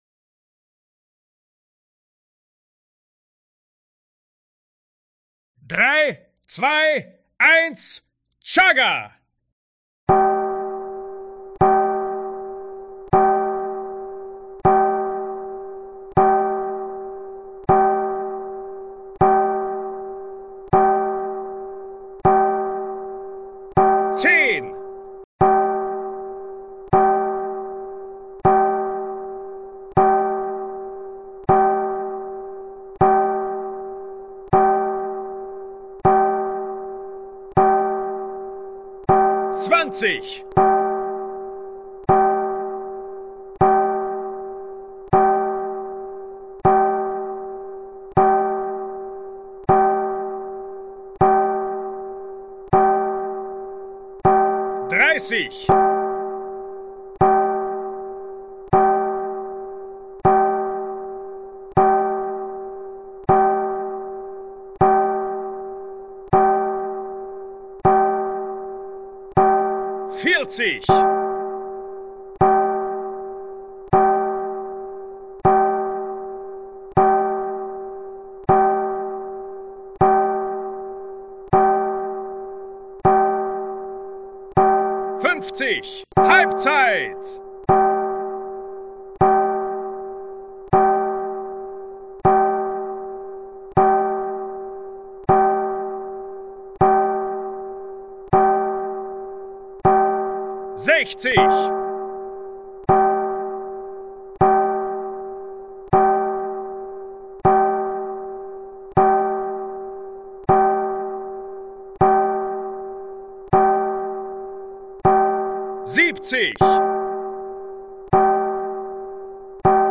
Ein Stein entspricht 1,5 Sekunden, wodurch eine Halbzeit 2:30 Minuten reine Spielzeit hätte.
Ich habe eine Sounddatei mit 100 Steinen und einem Counter erstellt. Mit Handy und Bluetooth-Lautsprecher könnt ihr immer in der Spielzeit die Steine hören und bekommt alle zehn Steine die Ansage, wie weit das Spiel fortgeschritten ist.
jugger_100_Steine_mit_Counter.mp3